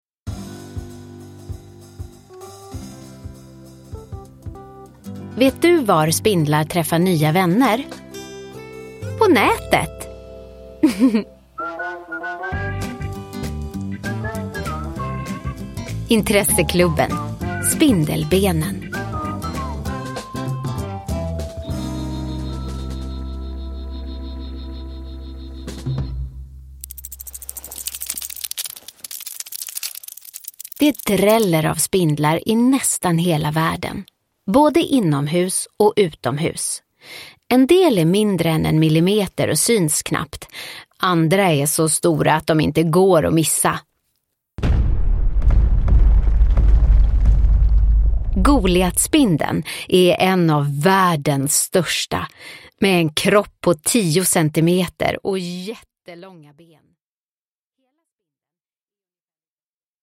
Spindelbenen – Ljudbok – Laddas ner